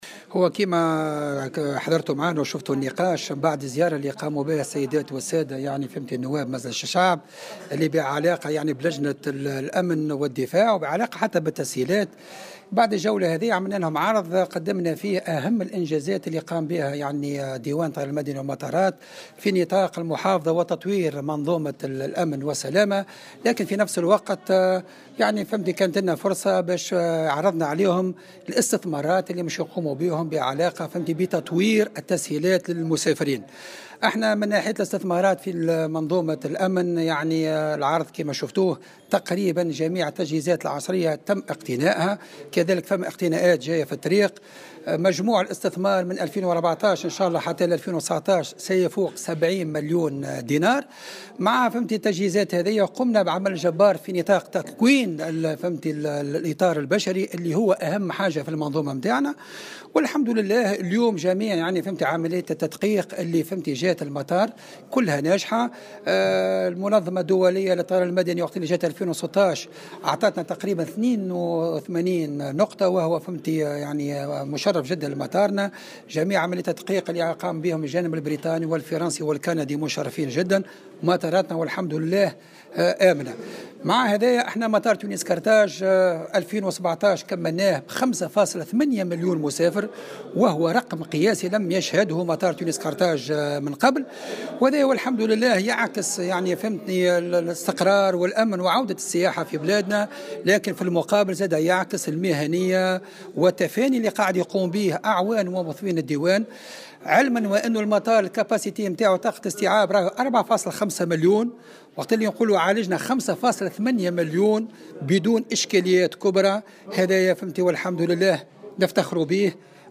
وأضاف في تصريح لمراسل "الجوهرة أف أم" على هامش زيارة ميدانية لعدد من نواب لجنة الأمن والدفاع ولجنة التونسيين بالخارج لمطار تونس قرطاج أن هذا الرقم يعكس حالة الاستقرار والأمن وعودة القطاع السياحي ومهنية أعوان وموظفي الديوان.